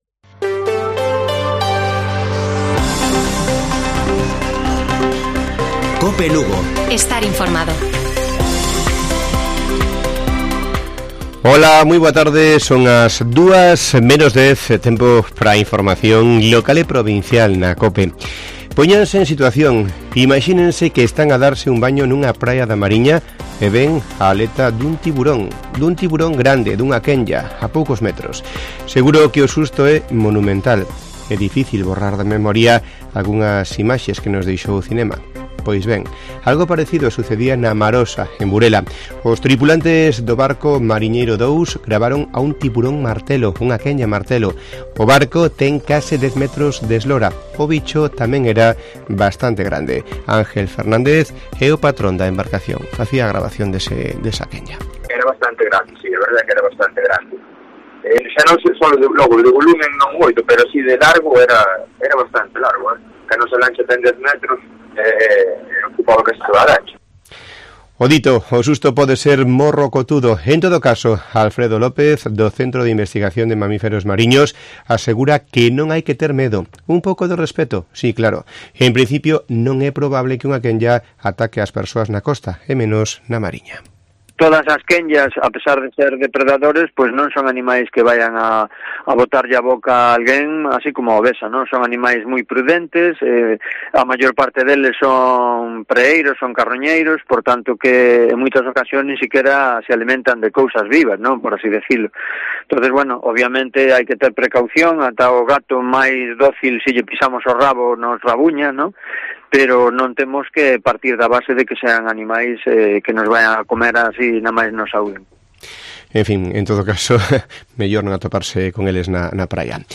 Informativo Mediodía de Cope Lugo. 13 de septiembre. 13:50 horas